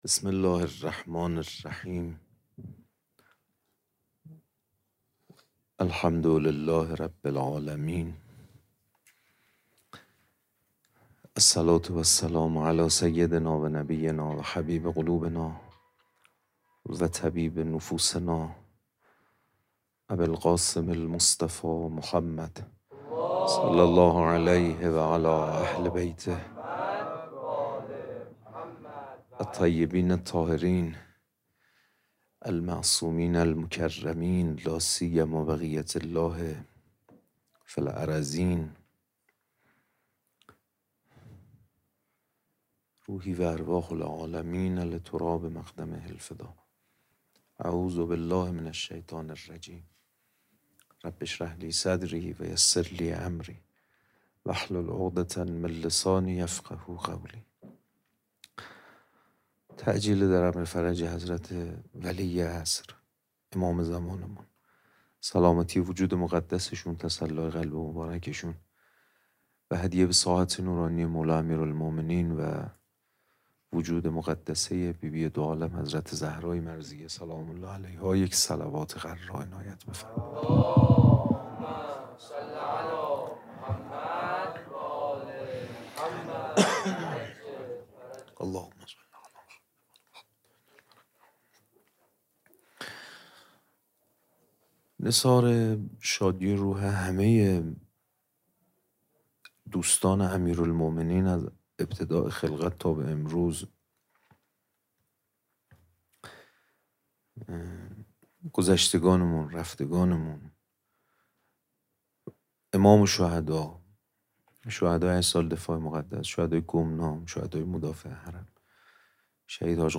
خیمه گاه - روضةالشهداء - سخنرانی